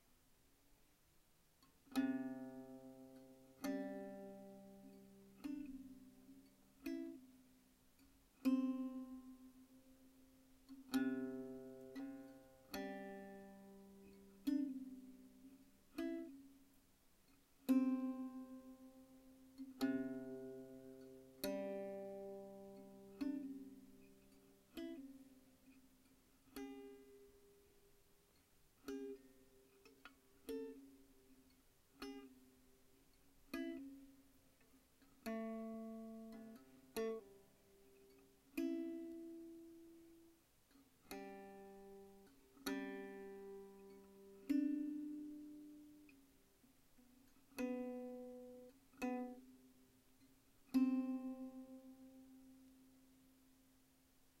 三味線
駒：象牙（オリジナル）